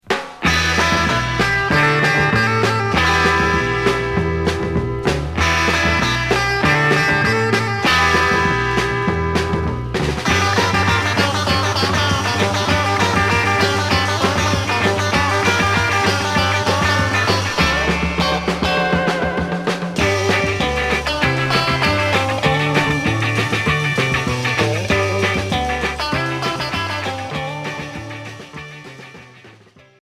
Rock instrumental